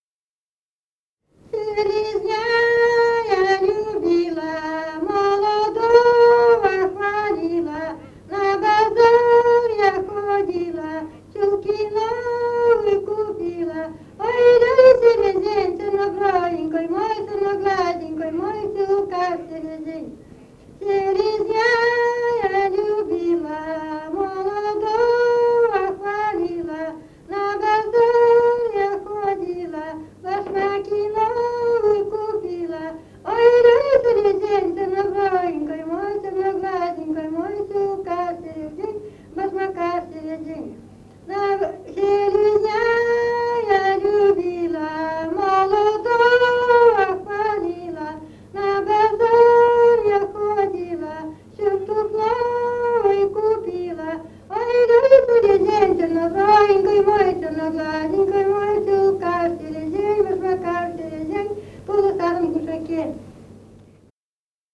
«Селезня я любила» (хороводная игровая).
в д. Малата Череповецкого района